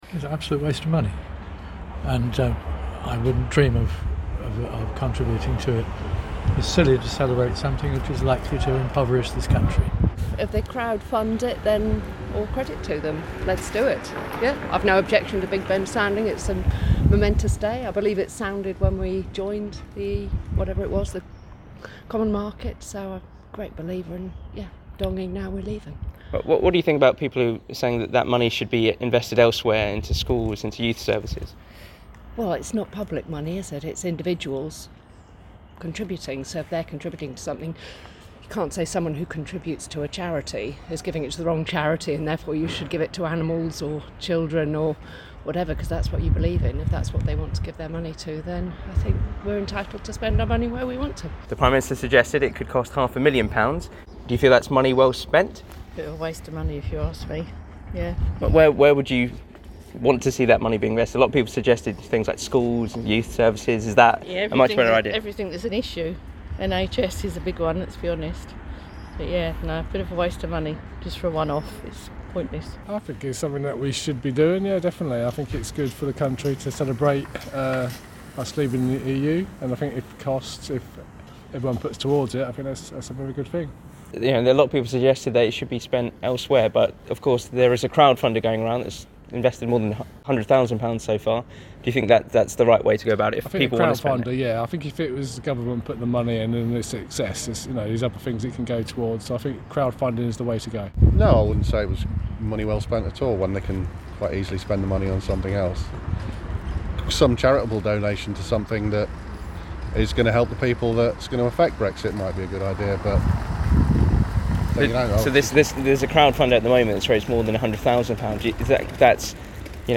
LISTEN: We asked these people in Rochester if ringing Big Ben to mark the UK's exit from the EU is a good idea - 20/01/20